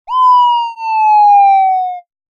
drop.ogg.mp3